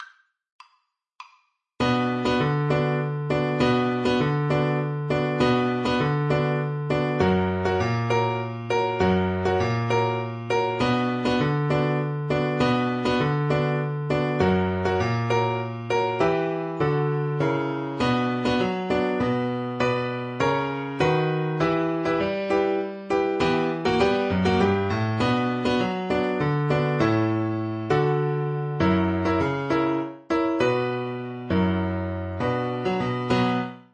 Play (or use space bar on your keyboard) Pause Music Playalong - Piano Accompaniment transpose reset tempo print settings full screen
Violin
3/4 (View more 3/4 Music)
D major (Sounding Pitch) (View more D major Music for Violin )
Traditional (View more Traditional Violin Music)
Argentinian